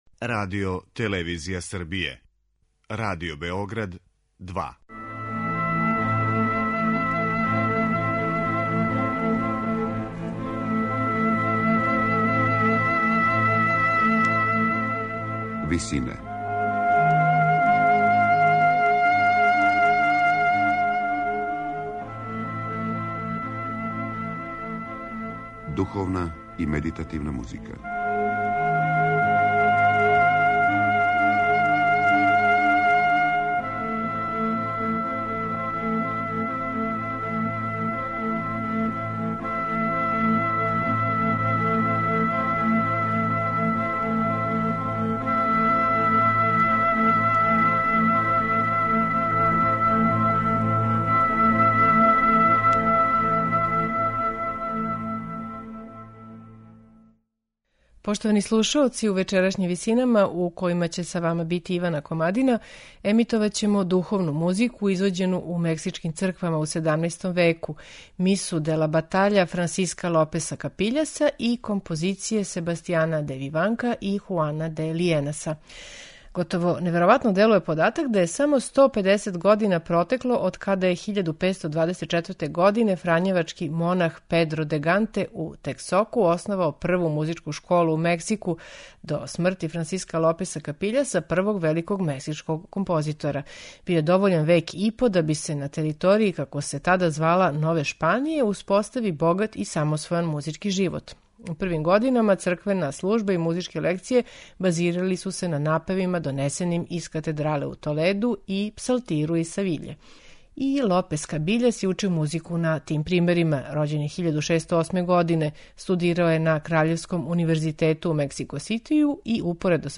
Емитоваћемо духовну музику извођену у мексичким црквама у 17. веку
Слушаћете их у интерпретацији коју је остварио вокални ансамбл De profundis из Монтевидеа